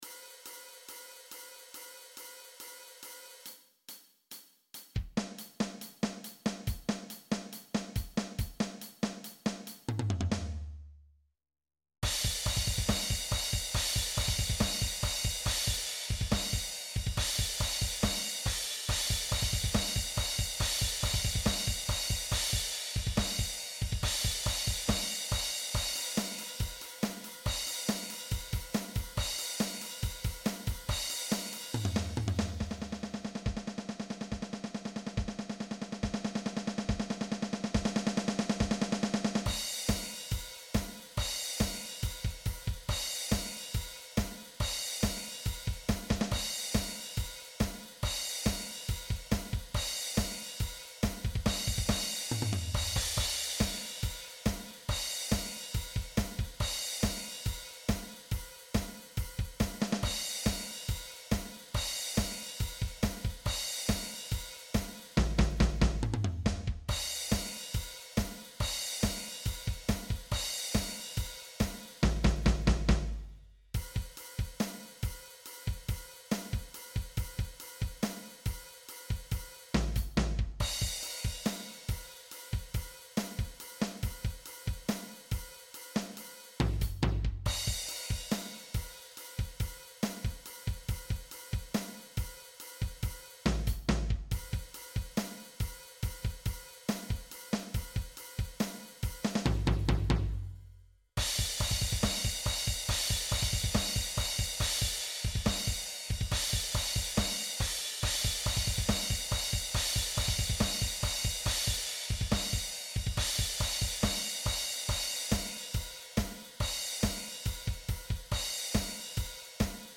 setlive-45mindrums.mp3